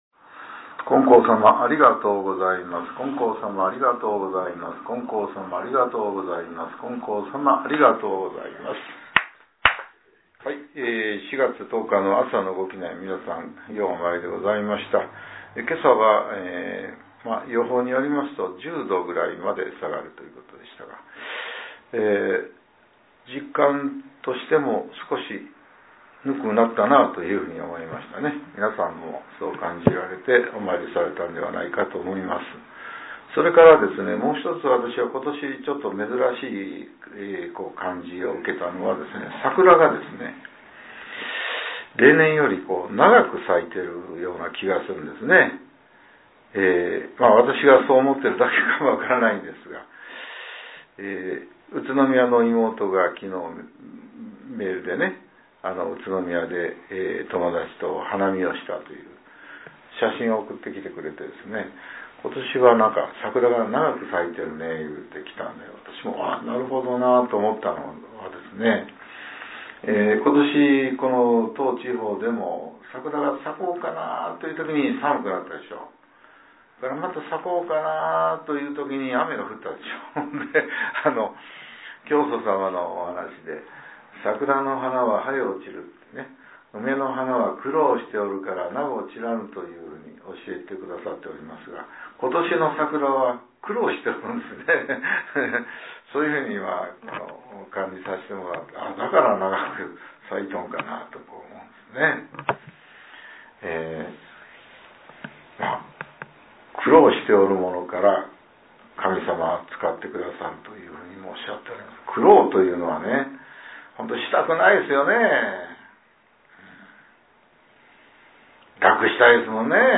令和７年４月１０日（朝）のお話が、音声ブログとして更新されています。